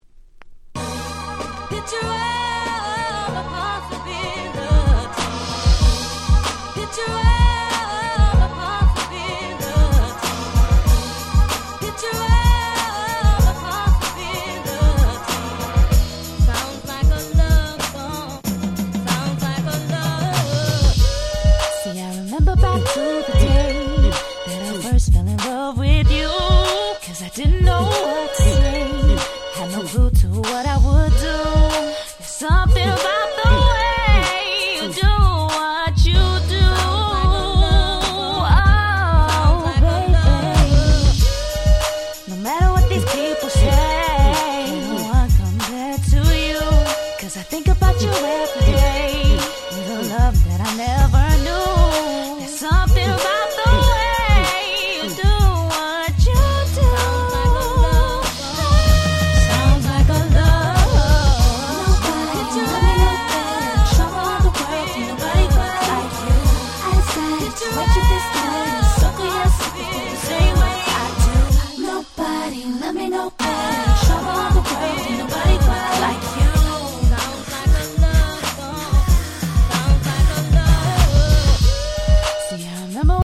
White Press Only Remixes !!
Hip Hop R&B 00's